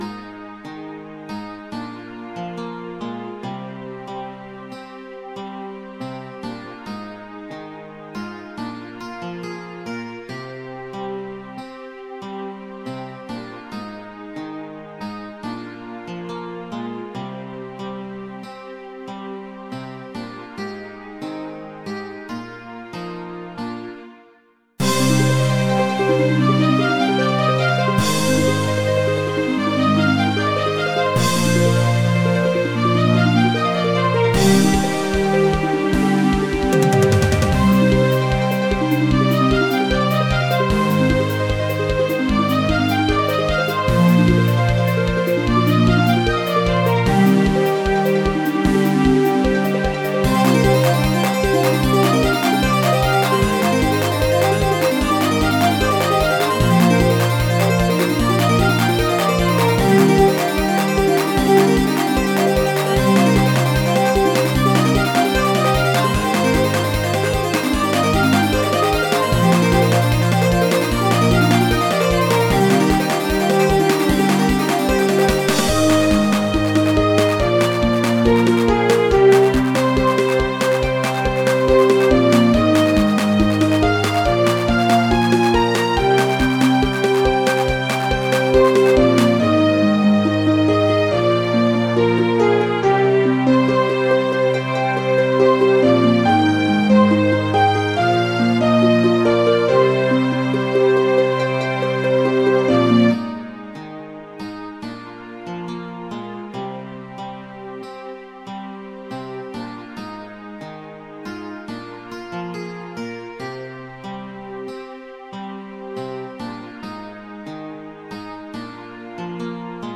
原游戏MIDI版，由Roland VSC-55导出。